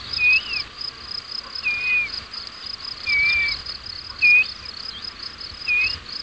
A fourth bird calls several times while flying low above the top of Monte Buceto.
E 11 33' - ALTITUDE: +1000 m. - VOCALIZATION TYPE: flight calls.
- COMMENT: A less common call type.